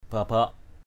/ba-baʔ/ 1. (d.) đg.) nịt = ceindre, ceinturer. babak talei kaing bbK tl] ki{U nịt dây lưng = mettre une ceinture; babak ân asaih bbK a;N a=sH thắng yên ngựa = mettre...